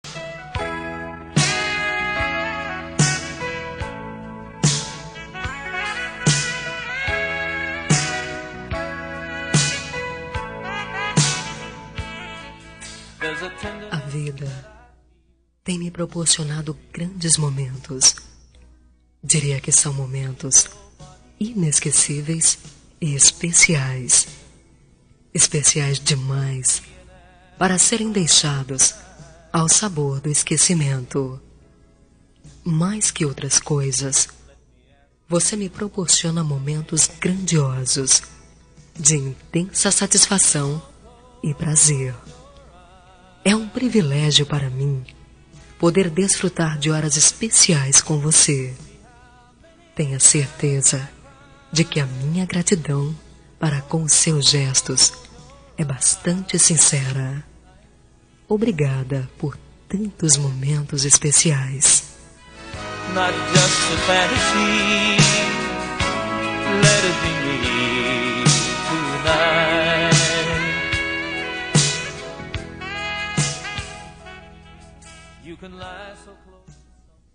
Telemensagem Momentos Especiais – Voz Feminina – Cód: 201880 – Obrigada pela Noite